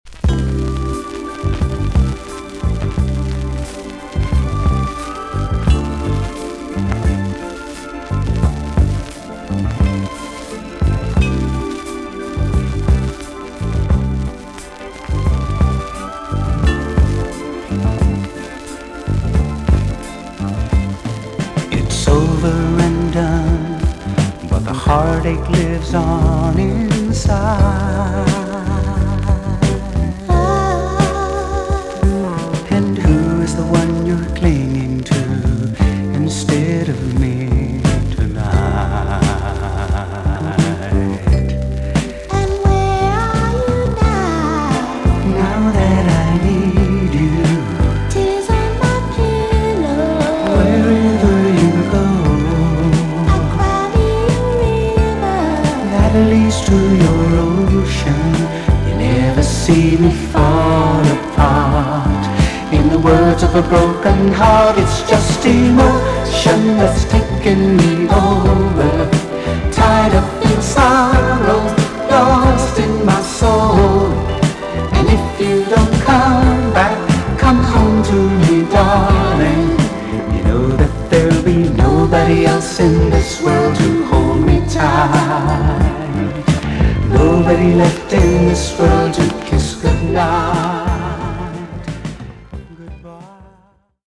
VG+~EX- 少し軽いチリノイズが入りますが良好です。